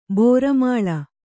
♪ bōra māḷa